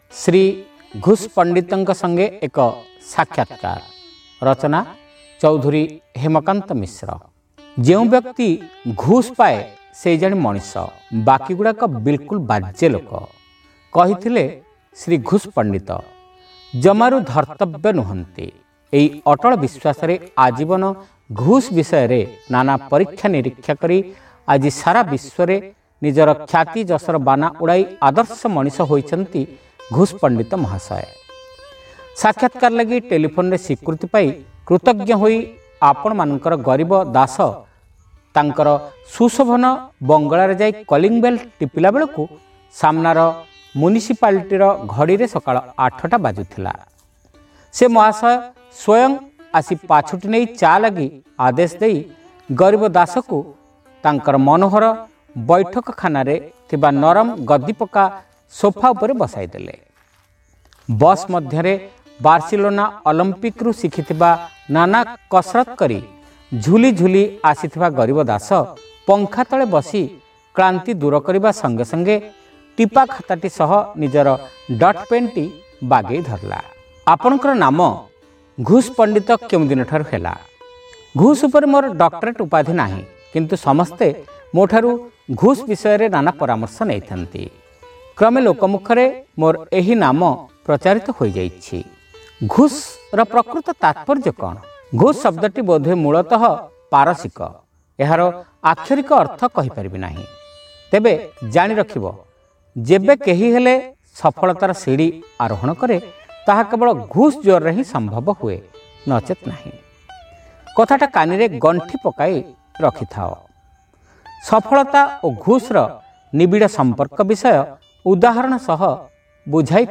Audio Story : Sri Ghus Panditanka Sange Eka Sakhyatkar